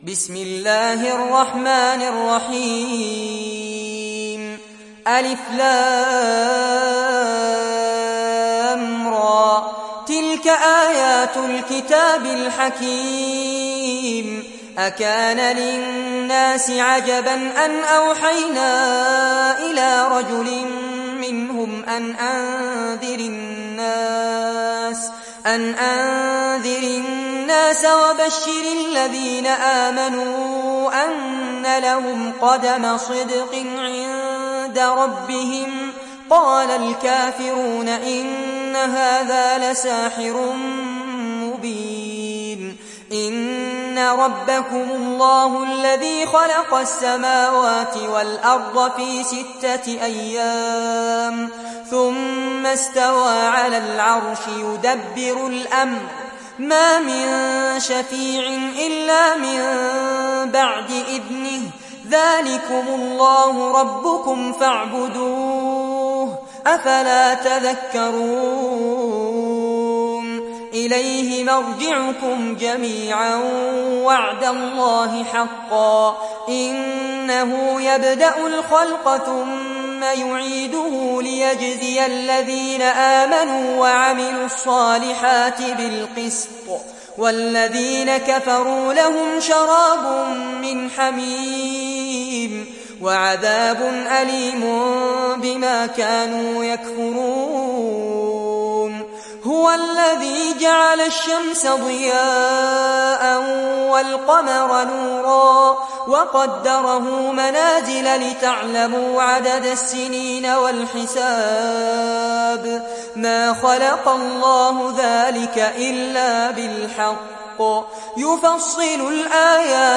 تحميل سورة يونس mp3 بصوت فارس عباد برواية حفص عن عاصم, تحميل استماع القرآن الكريم على الجوال mp3 كاملا بروابط مباشرة وسريعة